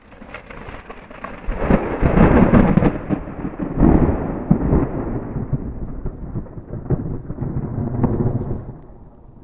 Thunder
thunder-3.ogg